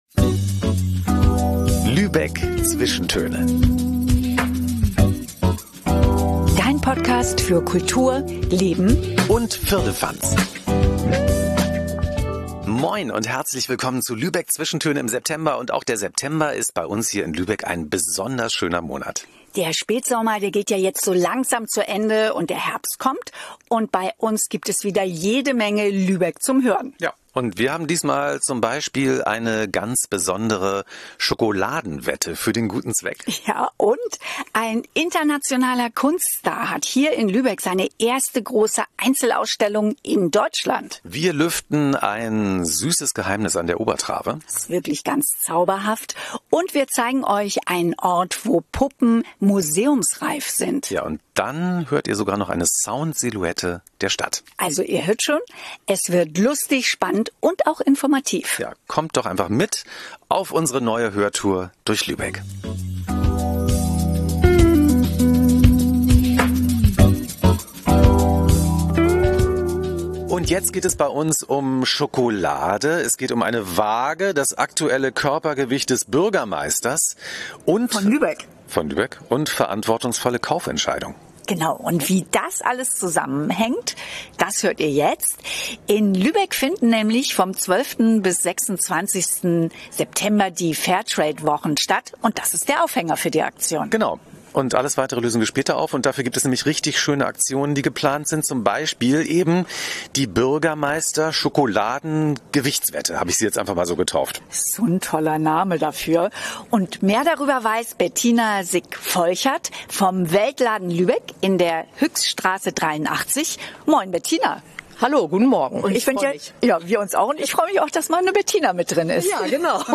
Außerdem hörst eine Sound-Silhouette der Stadt.